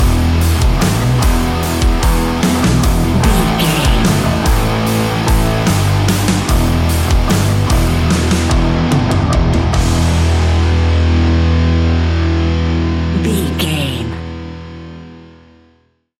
Ionian/Major
E♭
hard rock
instrumentals